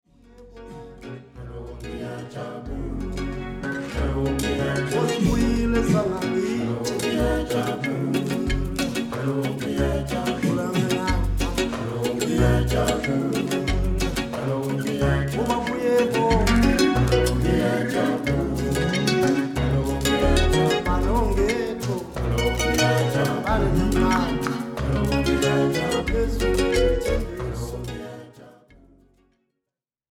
- Recorded and mixed at AVAF Studios, Zurich, Switzerland